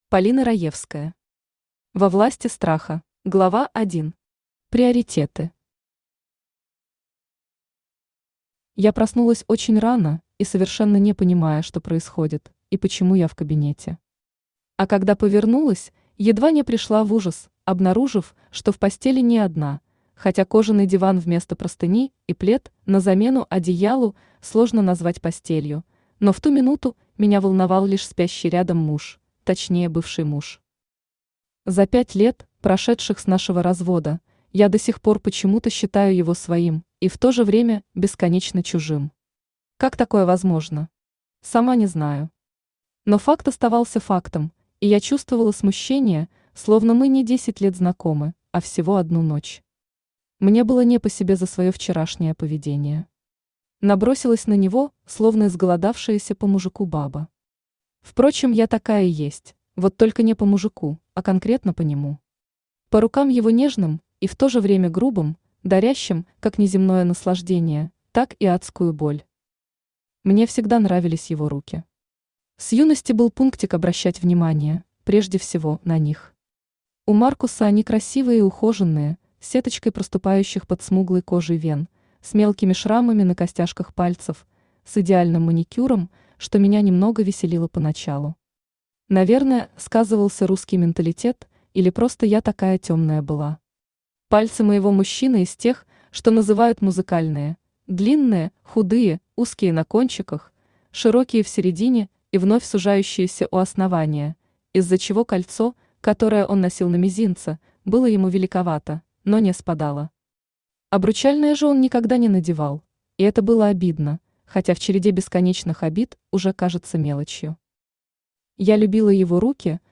Аудиокнига Во власти страха | Библиотека аудиокниг
Aудиокнига Во власти страха Автор Полина Александровна Раевская Читает аудиокнигу Авточтец ЛитРес.